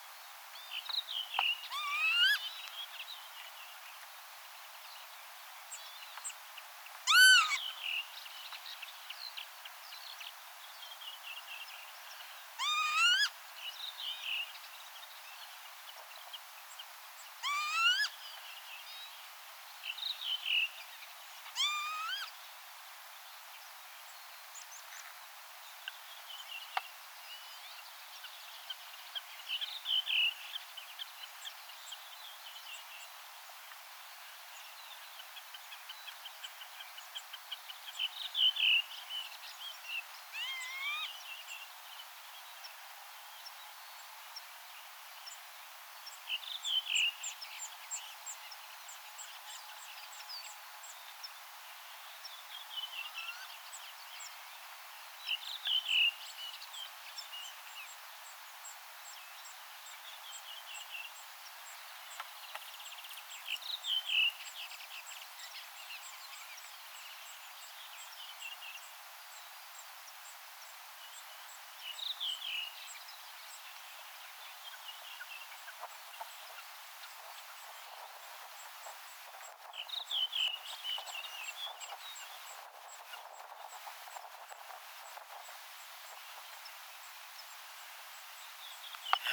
keltasirkun poikasen kerjuuääntä
Se eroaa pajusirkun poikasen kerjuuäänestä
siinä, että se on vähän sirisevää?
tassakin_ilmeisesti_keltasirkun_poikasen_kerjuuaantelya.mp3